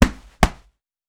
Gloves Block Counter.wav